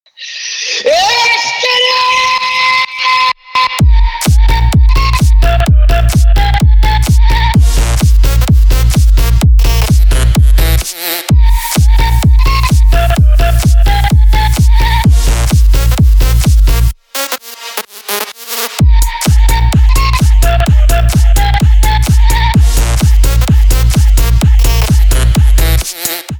• Качество: 128, Stereo
мужской голос
громкие
dance
клубняк
крик